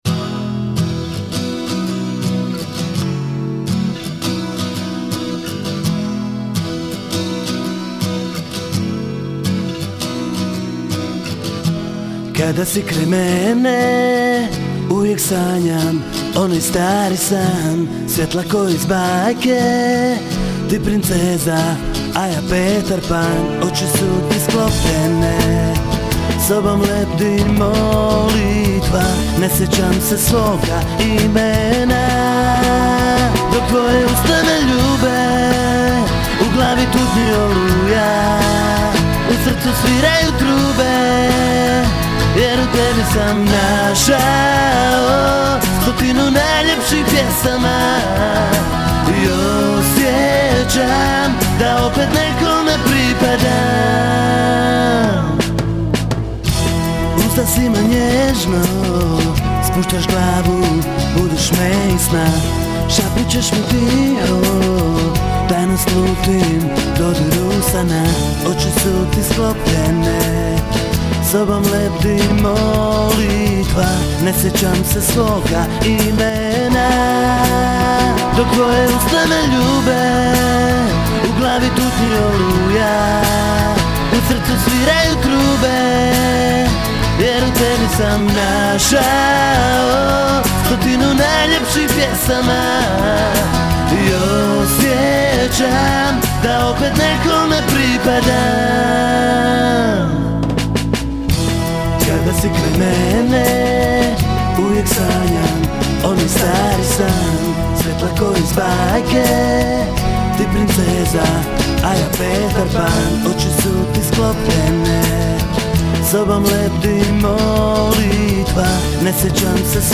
Rock - Pop